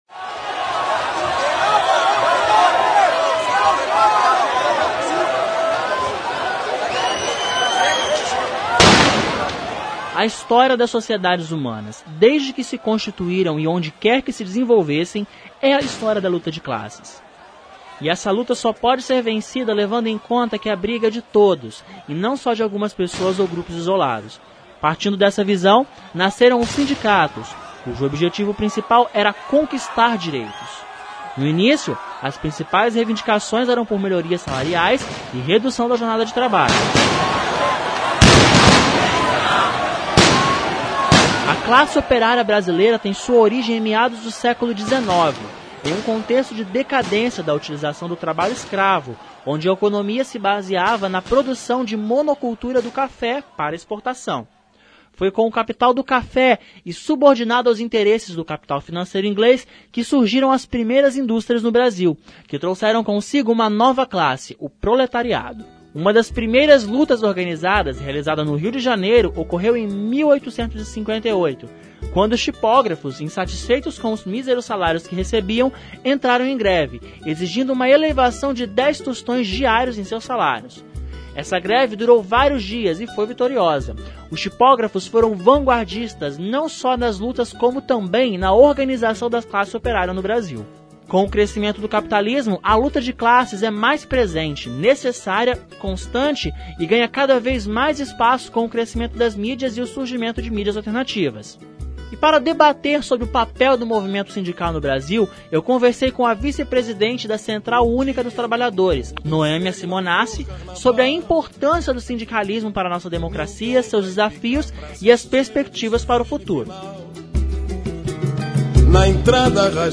reportagem_sindicatos_site.mp3